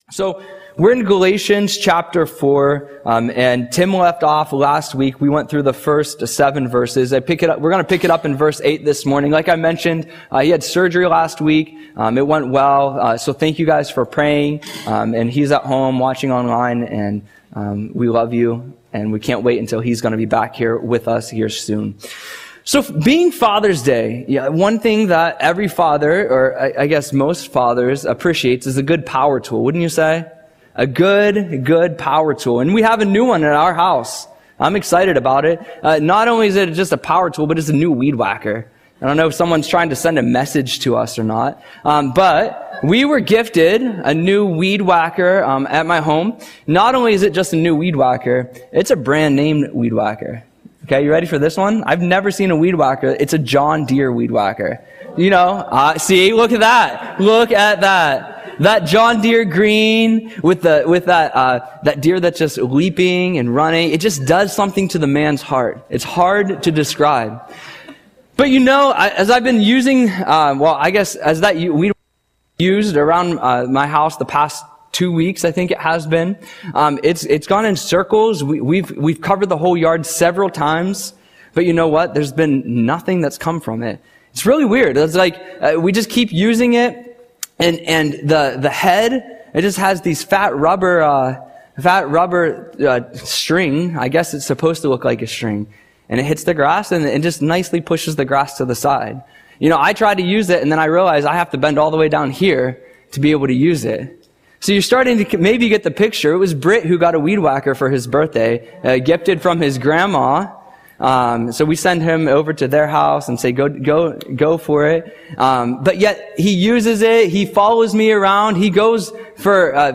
Audio Sermon - June 15, 2025